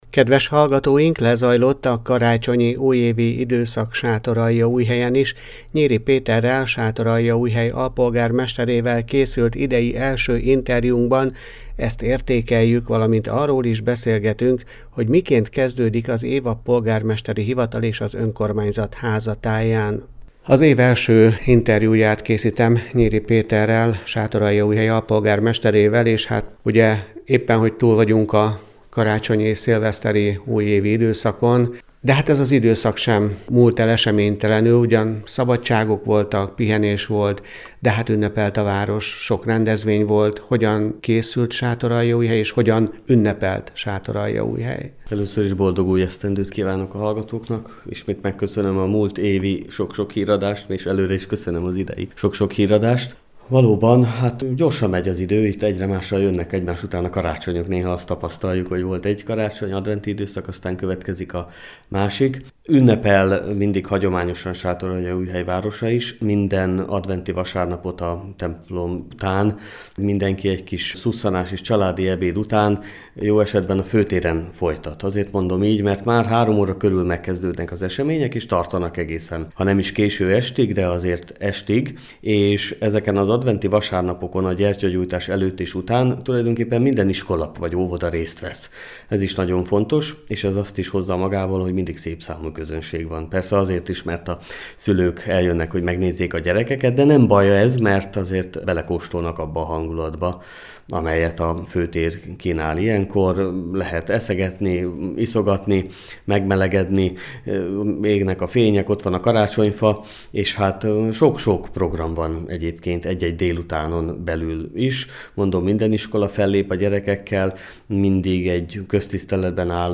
Lezajlott a karácsonyi-újévi időszak Sátoraljaújhelyen is. Nyiri Péterrel, Sátoraljaújhely alpolgármesterével készült idei első interjúnkban ezt értékeljük, valamint arról is beszélgetünk, hogy miként kezdődik az év a polgármesteri hivatal és az önkormányzat háza táján.